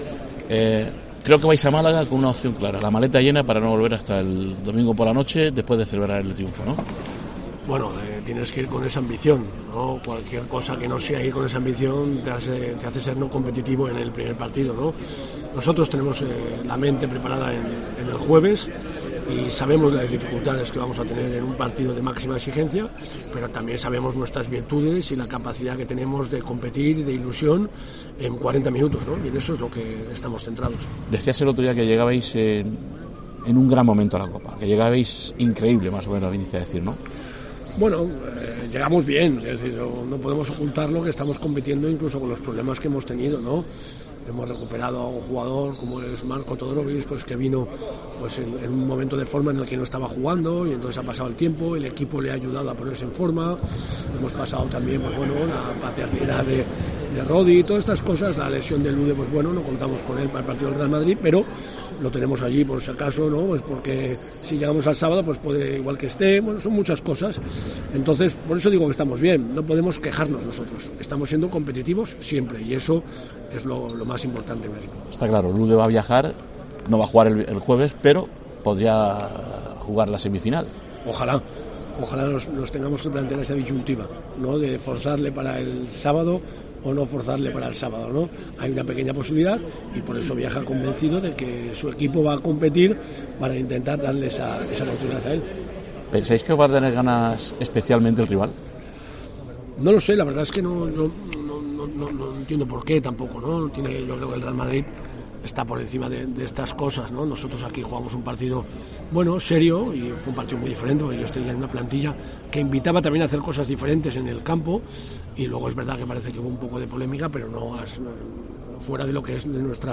ha confirmado en los micrófonos de COPE durante el Media Day previo